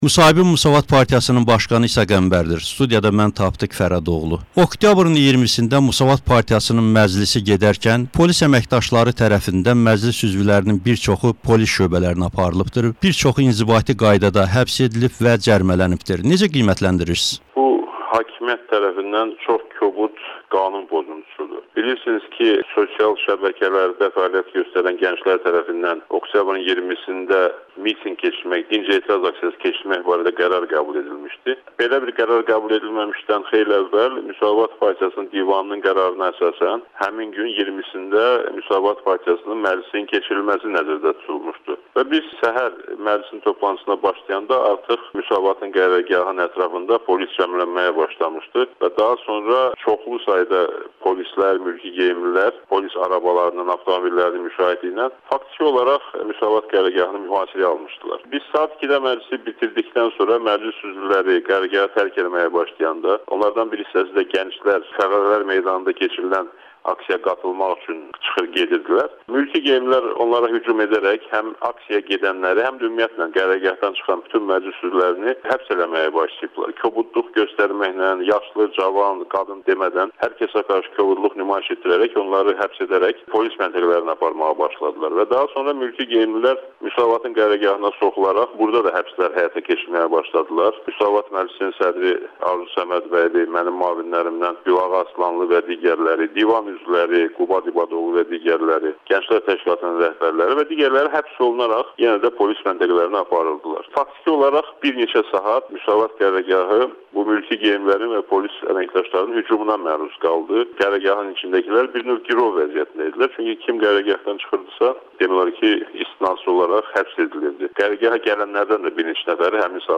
Müsavat Partiyasının başqanı İsa Qəmbərlə müsahibə